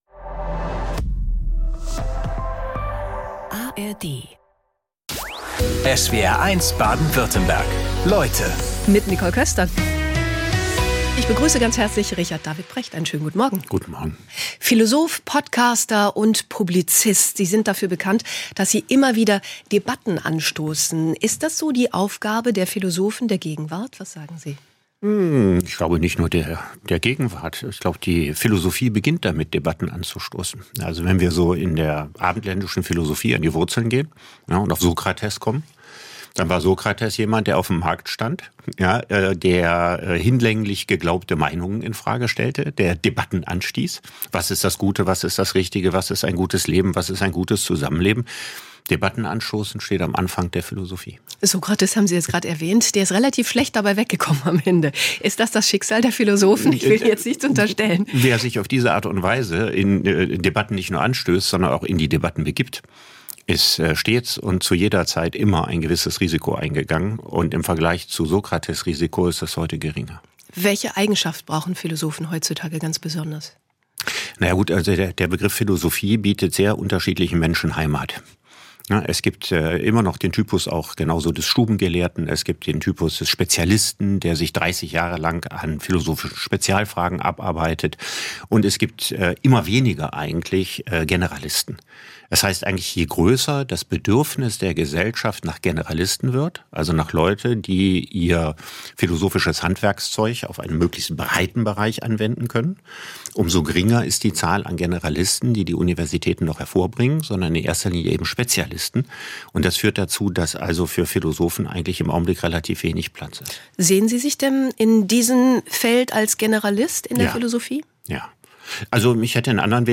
Talks mit besonderen Menschen und ihren fesselnden Lebensgeschichten aus Politik, Sport, Wirtschaft oder Wissenschaft.